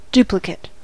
duplicate-noun: Wikimedia Commons US English Pronunciations
En-us-duplicate-noun.WAV